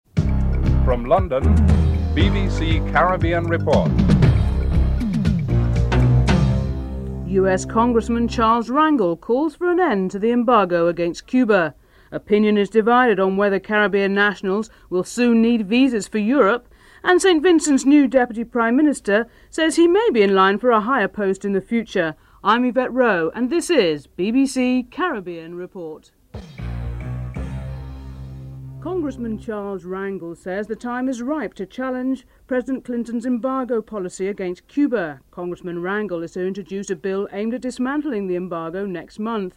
10. Wrap up and theme music (14:33-14:46)